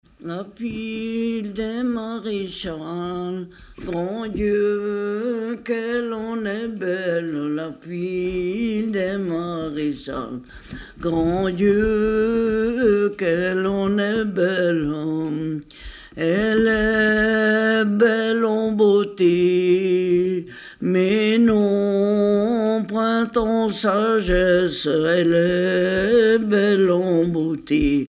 Mémoires et Patrimoines vivants - RaddO est une base de données d'archives iconographiques et sonores.
Catégorie Pièce musicale inédite